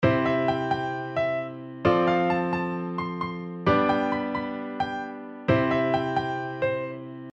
先ほどのメロディーを、CFGCに合わせてコードトーンだけにしてみます。
コードトーンだけの場合
先ほどとくらべて、童謡的というか素人臭いというか、安っぽく聞こえます。